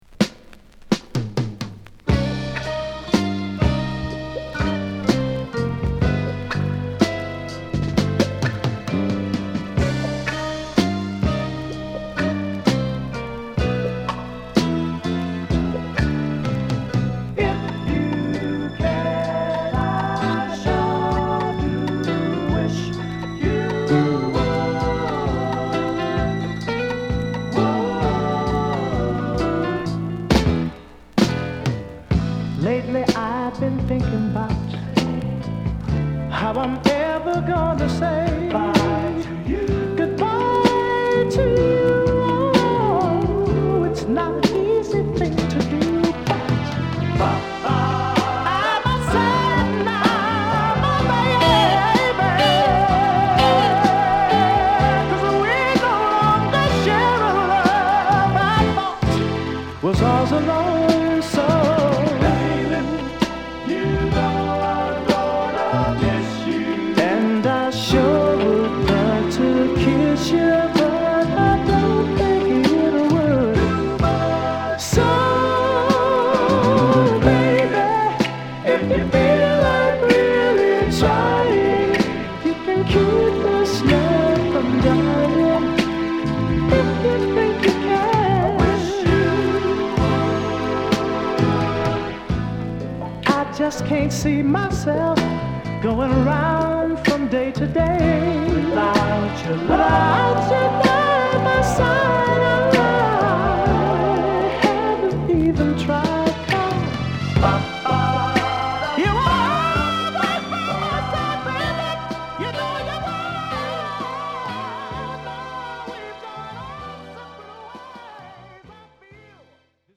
イースト・セントルイス発のソウルグループ
哀愁のギターを絡めたスウィートソウルで、ファルセットのリードもコーラスもバッチリ！